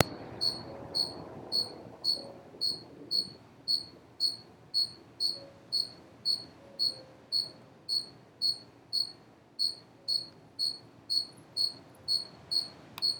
cigarra.wav